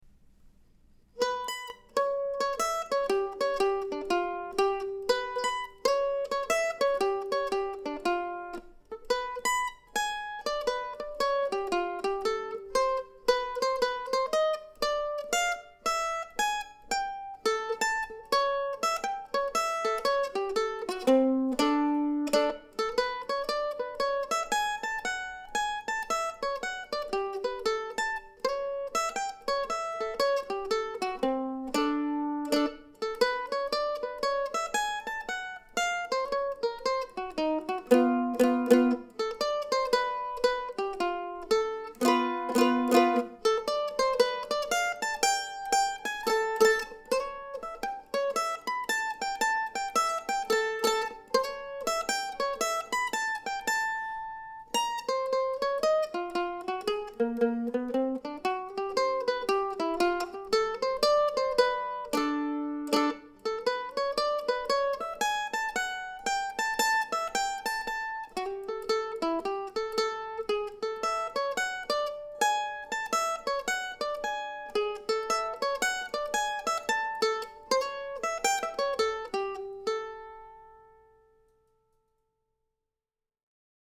I used this mando for this week's recordings as well, along with a new set of Thomastik strings.